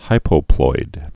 (hīpō-ploid)